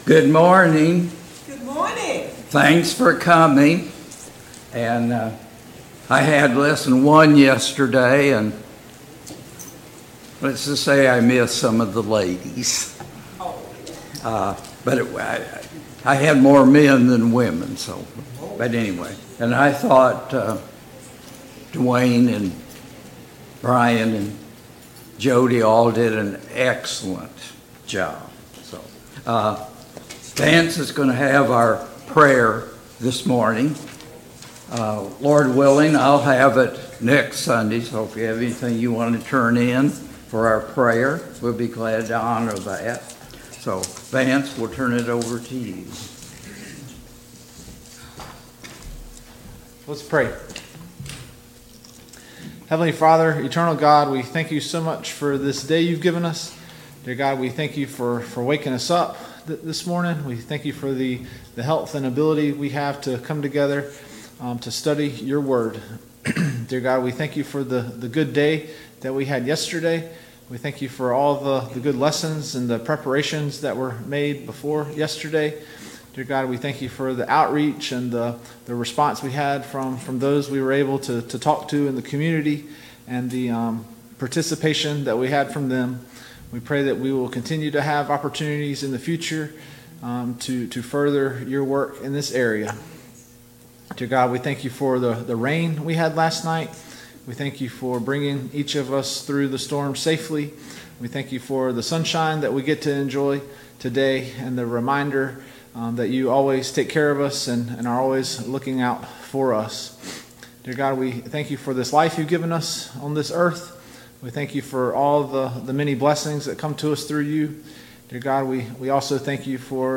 2 Corinthians 10 Service Type: Sunday Morning Bible Class Topics: Paul's Ministry « 18.